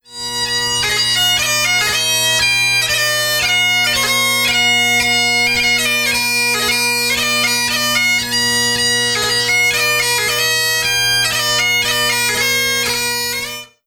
Gaita escocesa
aerófono
viento
gaita